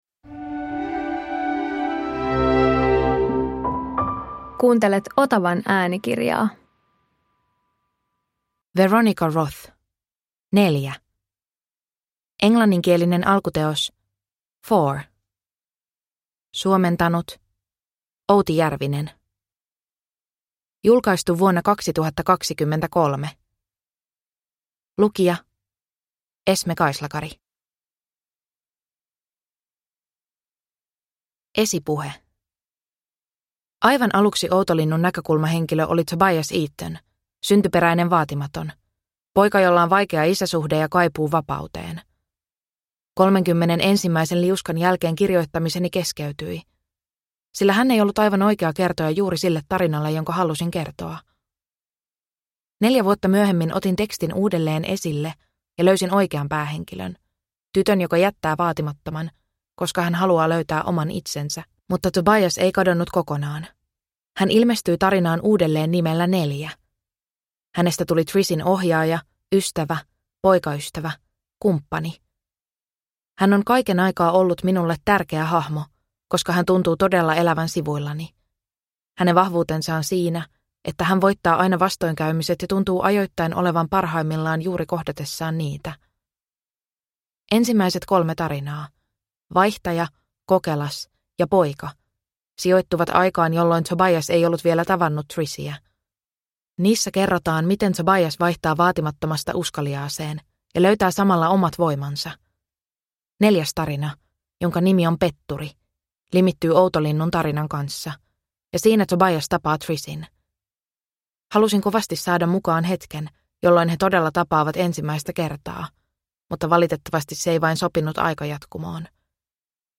Neljä – Ljudbok – Laddas ner